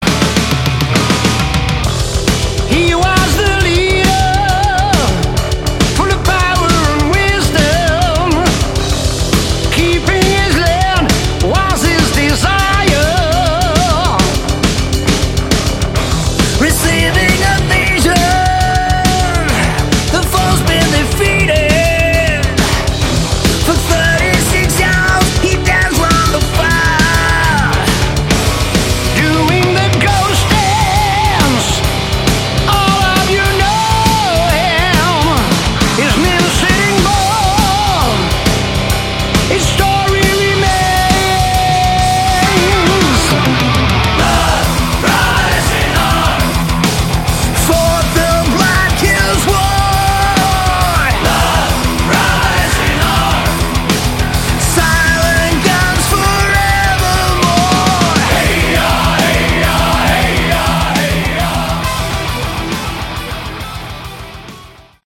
Category: Melodic Metal
guest vocals
guest guitars
guest bass
guest drums